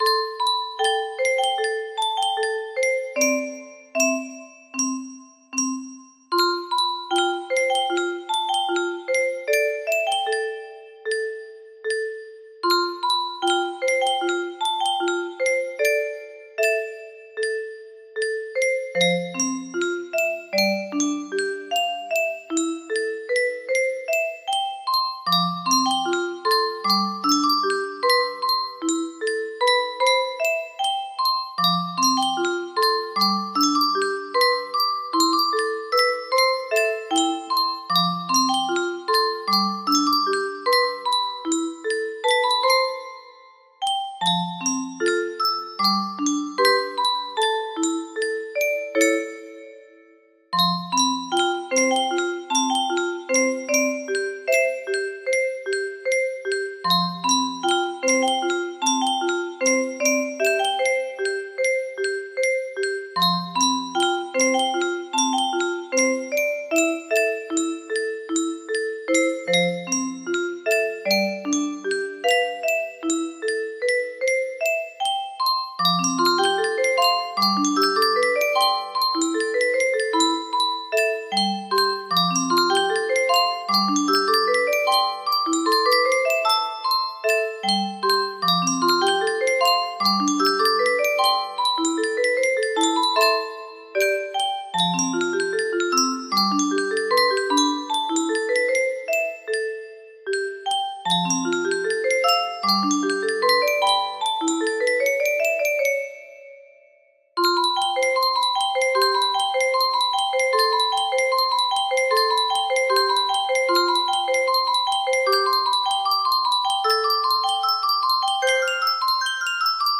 E1 music box melody
Grand Illusions 30 (F scale)